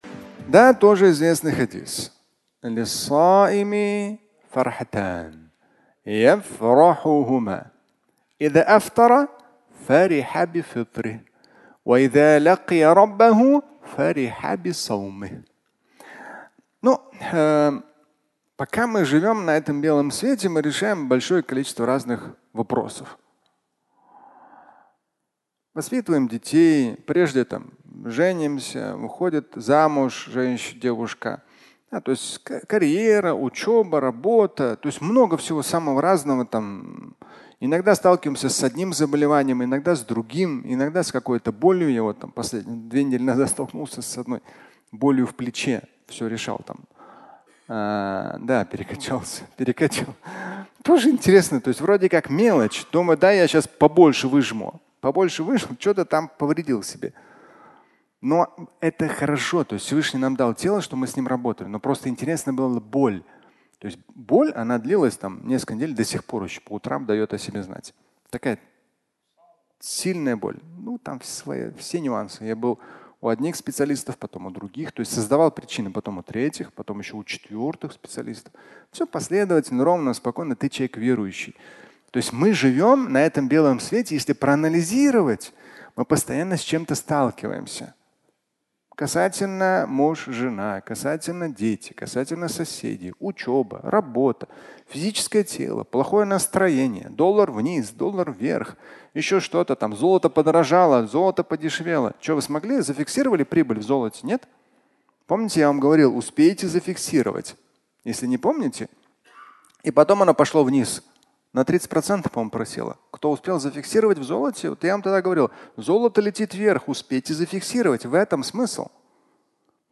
Радость поста (аудиолекция)
Фрагмент пятничной лекции